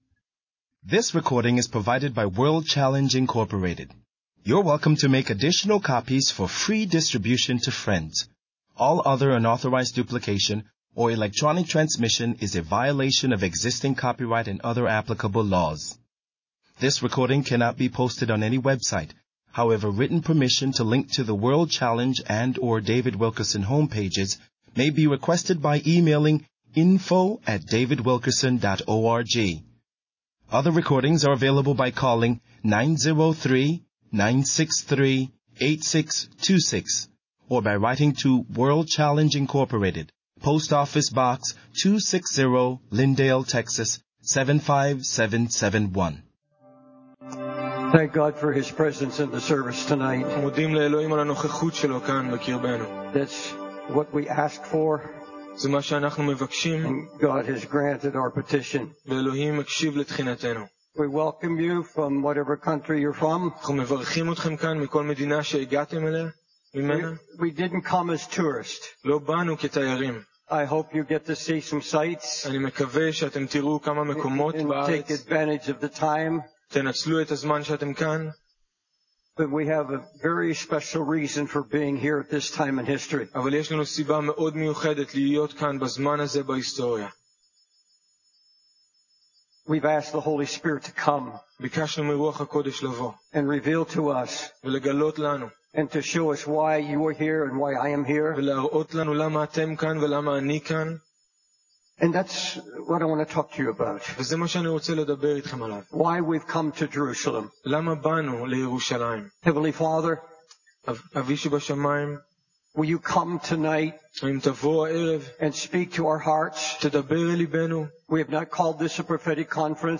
In this sermon, David Wilkerson emphasizes the importance of Christians recognizing their debt to Israel. He highlights the significance of Israel in God's plan, referencing the glory that fell on the tabernacle and the types and shadows of Jesus Christ found in the Old Testament.